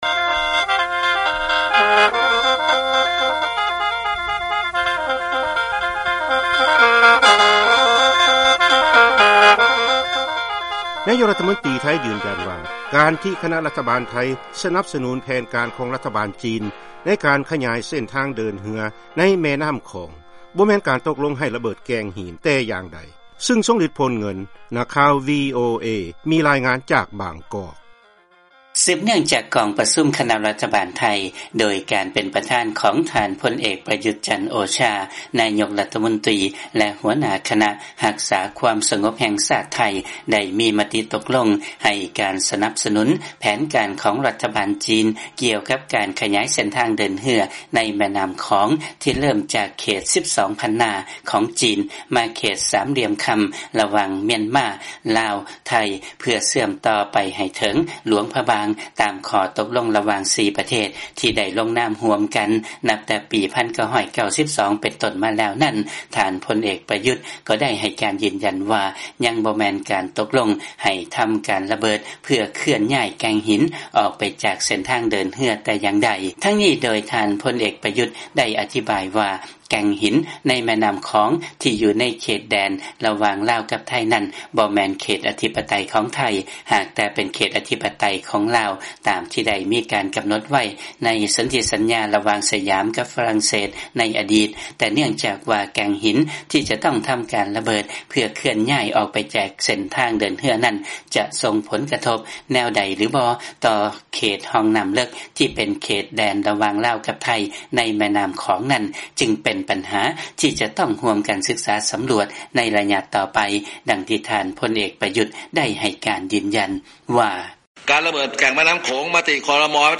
ເຊີນທ່າຮັບຟັງຂ່າວກ່ຽວກັບການລະເບີດແກ້ງຫີນ